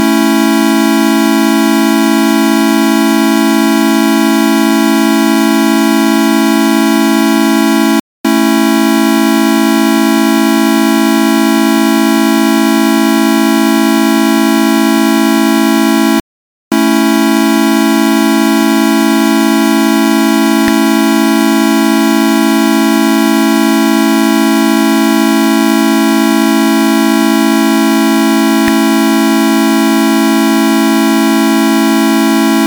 Equal temperament and just intonation compared with square waveform A pair of major chords. The first is in equal temperament; the second is in just intonation. The pair of chords is repeated with a transition from equal temperament to just intonation between the two chords. In the equal temperament chords a roughness or beating can be heard at about 4 Hz and about 0.8 Hz. In the just intonation triad, this roughness is absent. The square waveform makes the difference between equal temperament and just intonation more obvious.
A-major-triad-equal-temperament-compared-to-just-intonation-6-2008C.ogg.mp3